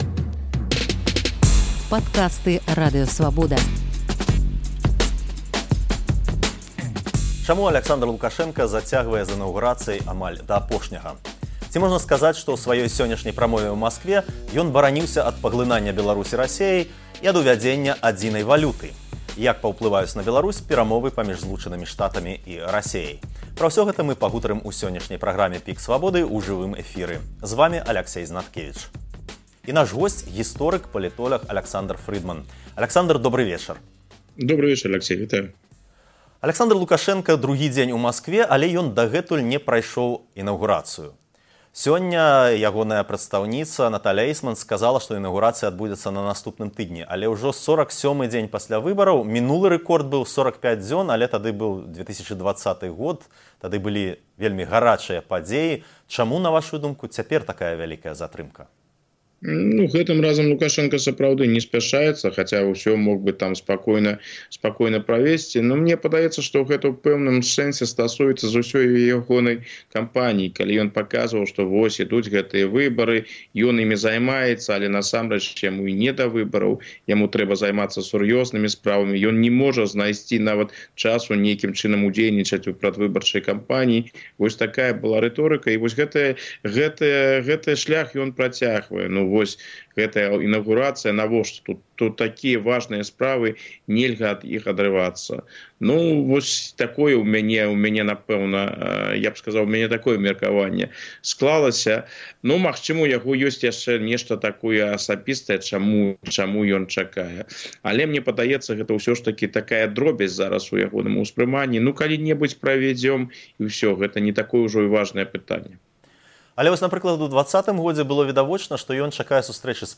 Як паўплываюць на Беларусь перамовы паміж ЗША і Расеяй? Разважае гісторык, палітоляг